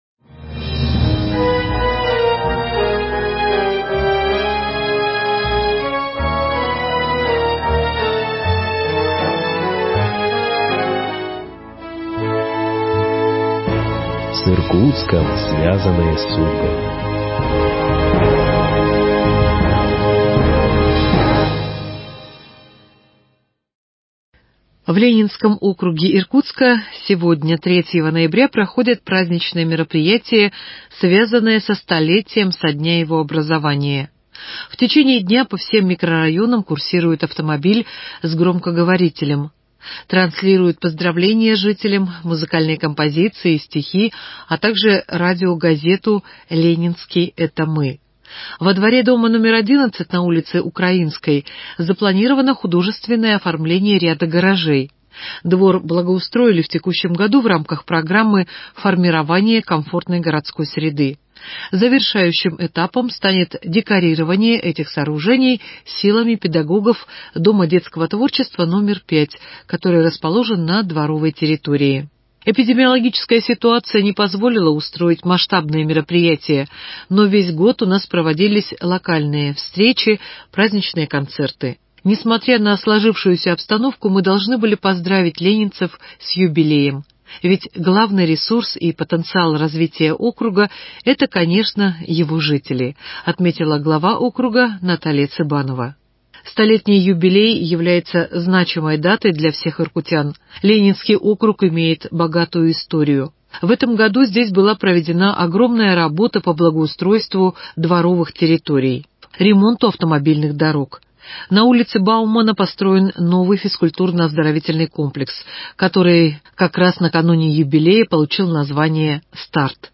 Поздравление мэра в честь 100-летия Ленинского округа Иркутска.